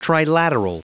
Prononciation du mot trilateral en anglais (fichier audio)
Prononciation du mot : trilateral